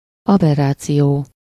Ääntäminen
IPA: [a.bɛ.ʁa.sjɔ̃]